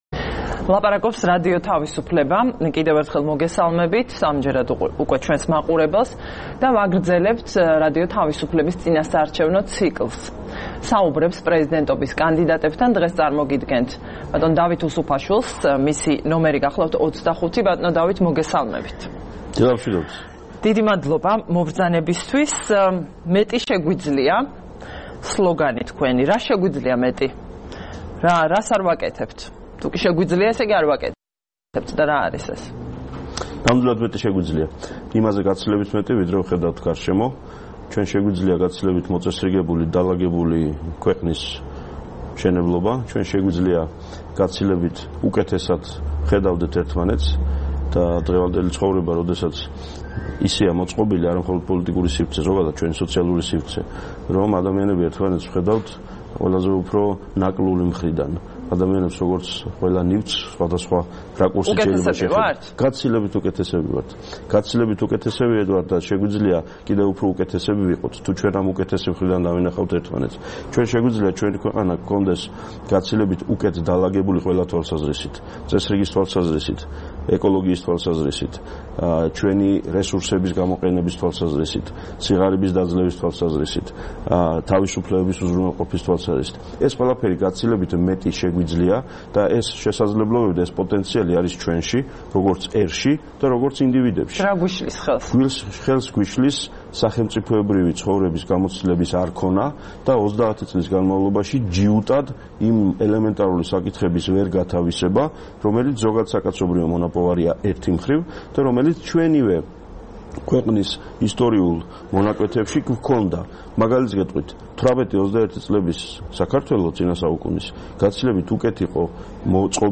საუბარი პრეზიდენტობის კანდიდატთან: დავით უსუფაშვილი, N 25
17 ოქტომბერს რადიო თავისუფლების "დილის საუბრების" სტუმარი იყო დავით უსუფაშვილი, პრეზიდენტობის კანდიდატი, N 25.